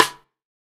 BR Sidestick.WAV